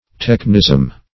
technism - definition of technism - synonyms, pronunciation, spelling from Free Dictionary
technism - definition of technism - synonyms, pronunciation, spelling from Free Dictionary Search Result for " technism" : The Collaborative International Dictionary of English v.0.48: Technism \Tech"nism\, n. Technicality.